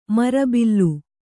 ♪ marabillu